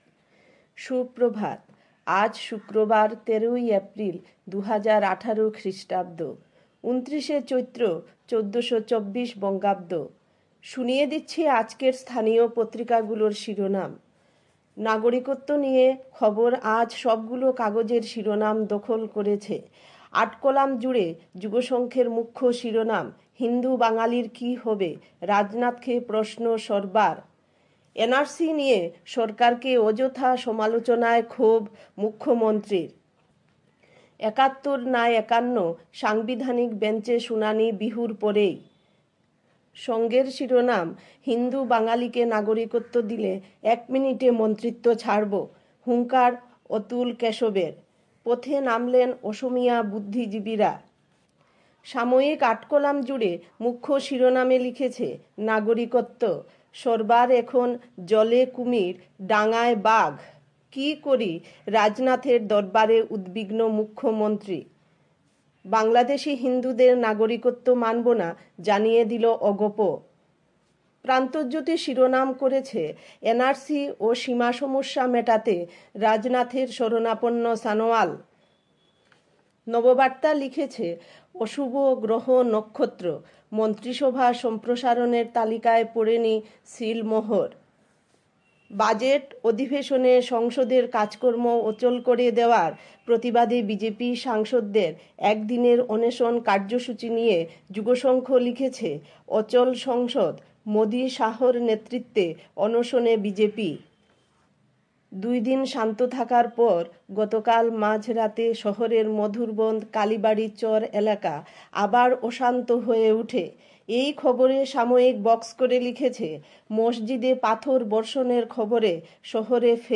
A quick bulletin with all top news.